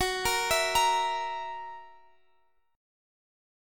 F#7 Chord (page 2)
Listen to F#7 strummed